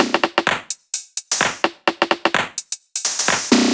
VFH3 128BPM Resistance Kit 9.wav